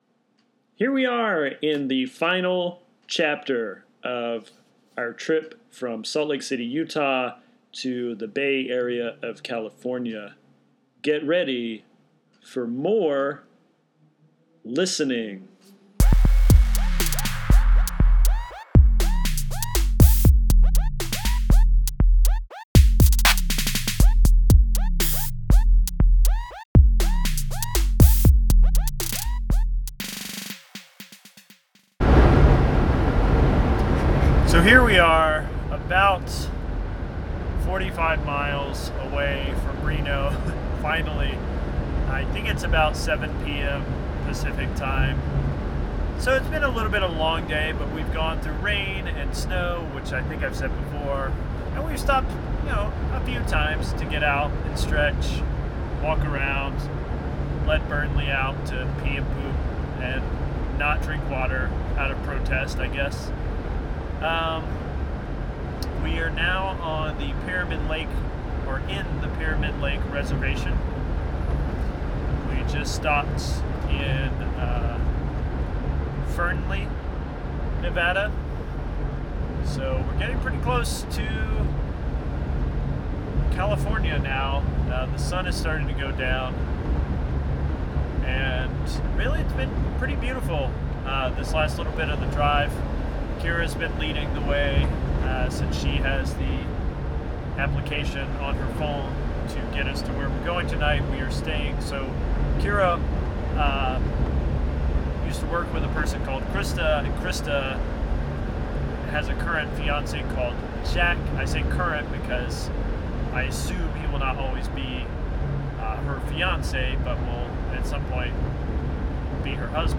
In April of 2017, my partner and I drove from Salt Lake City, UT to Walnut Creek, CA to live. I drove the moving truck and took along my digital recording device. Here is the finale of my rumbly ramblings.